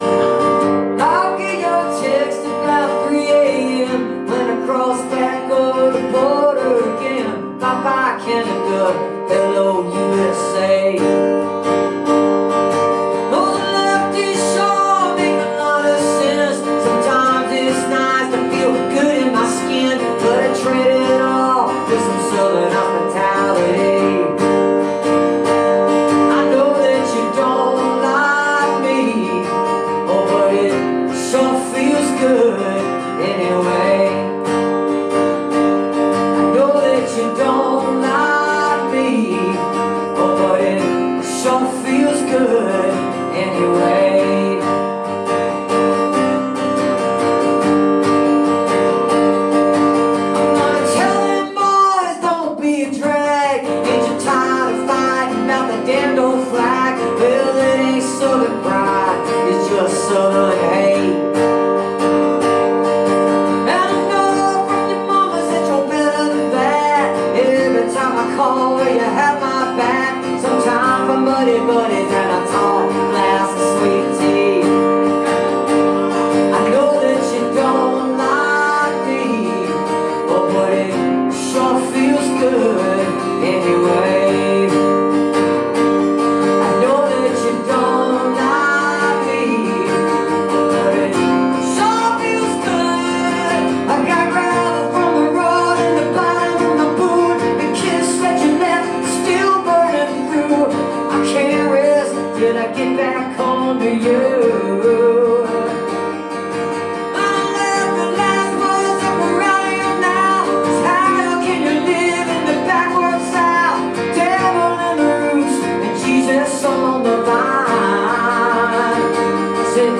(audio capture from a youtube video)